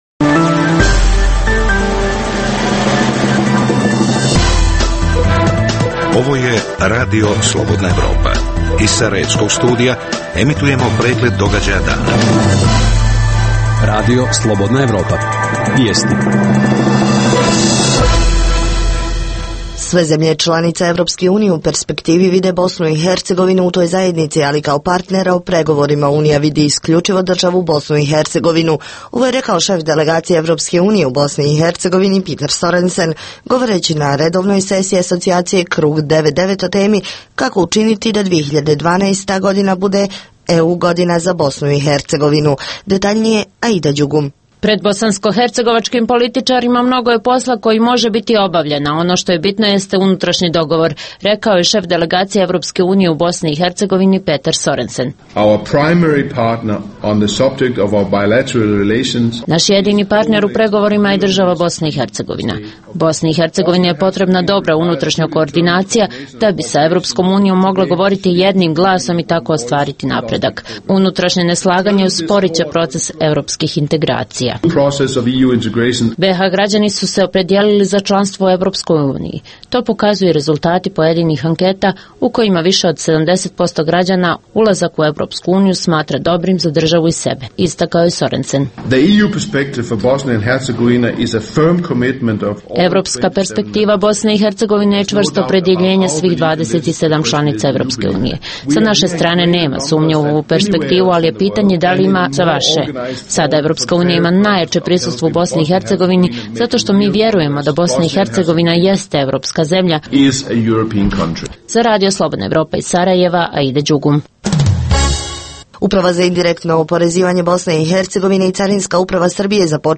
Intervju